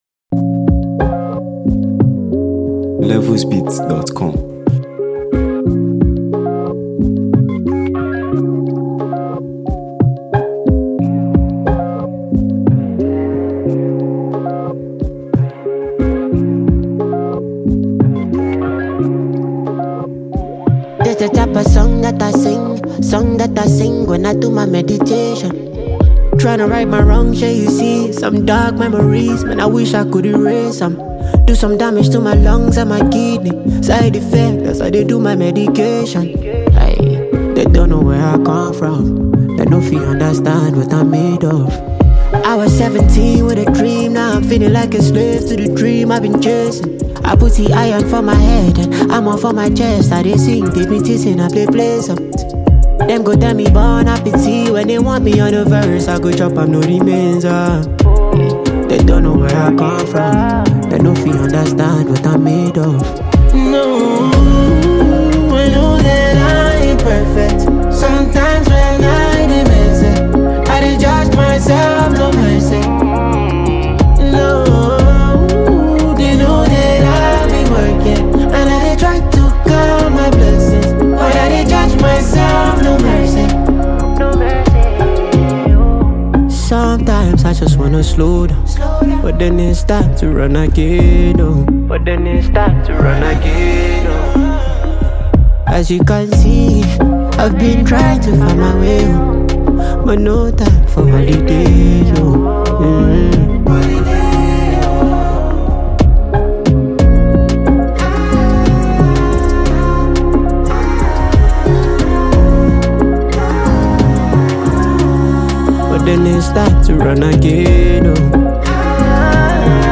Nigeria Music 2025 3:37